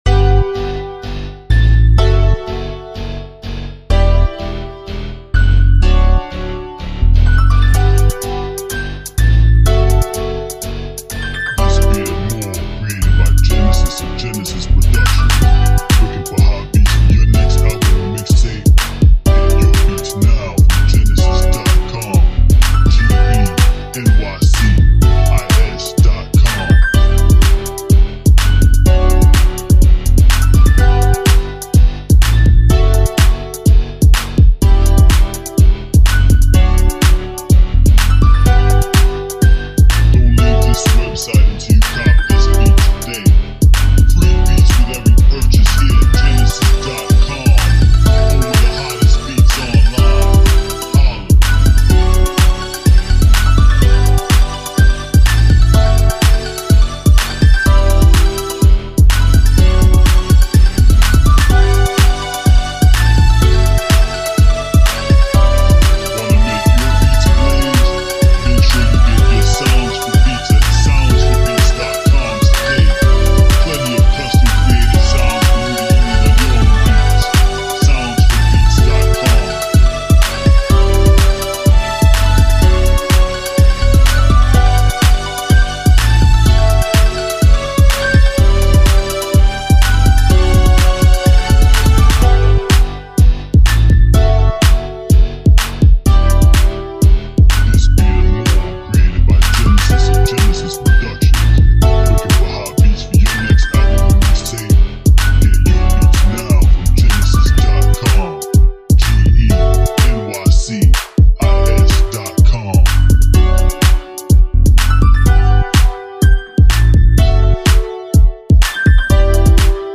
Uptempo Dance Beat